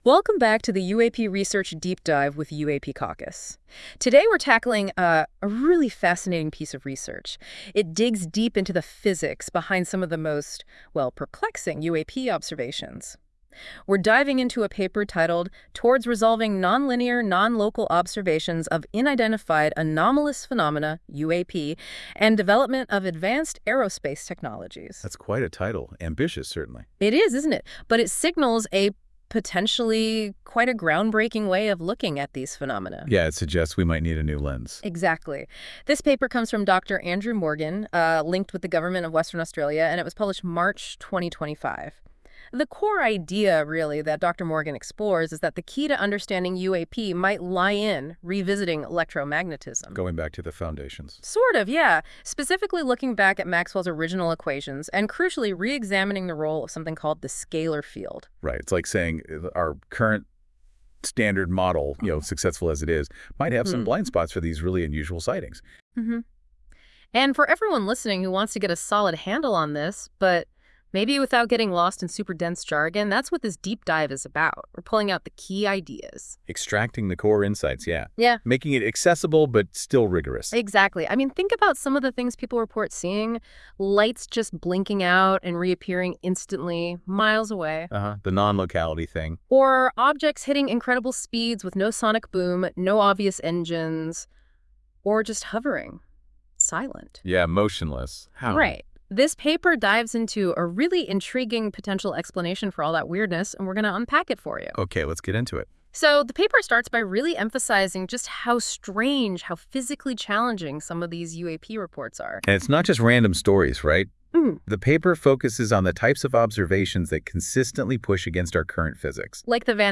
This AI-generated audio may not fully capture the research's complexity.